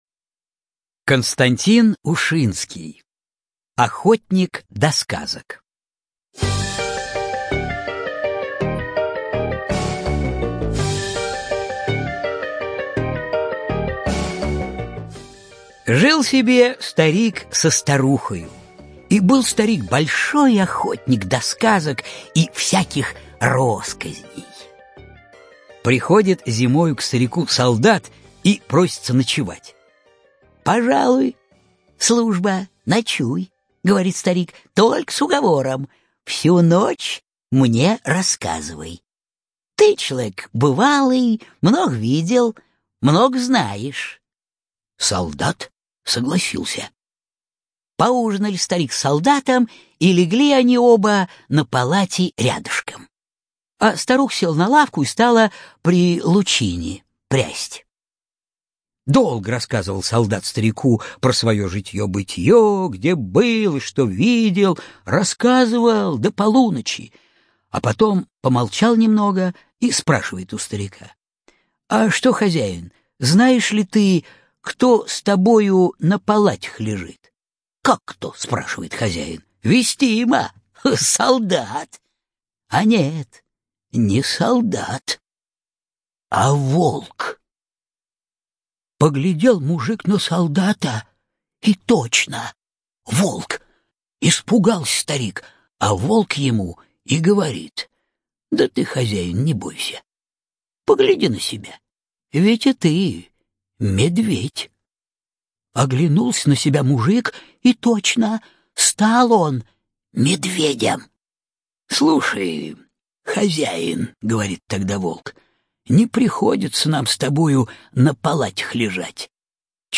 Охотник до сказок – аудиосказка Ушинского К.Д. Один мужик наслушался солдатских сказок, и ему приснился сон, будто он стал медведем…